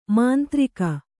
♪ māntrika